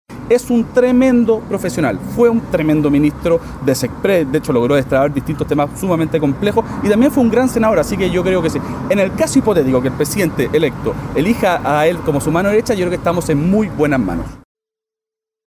Ante esto, el diputado electo Jaime Coloma (UDI) aseguró que Alvarado cuenta con la experiencia necesaria para convertirse en la mano derecha de José Antonio Kast.